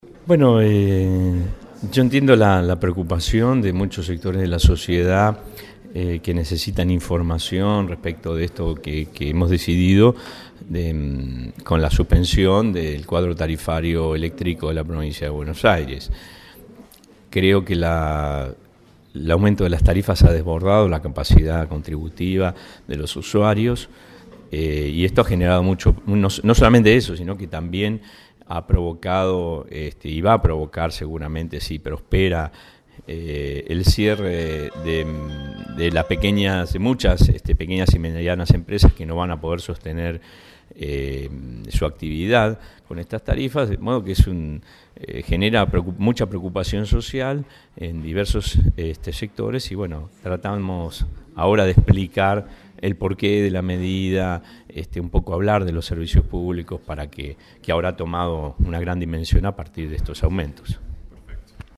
(23/06/2016) La actividad contó con la participación del Juez en lo Contencioso Administrativo Luis Federico Arias, quien ordeno frenar el tarifazo de energía eléctrica en todo el territorio de la provincia de Buenos Aires, y en diálogo con el móvil de Radio Estación Sur expresó: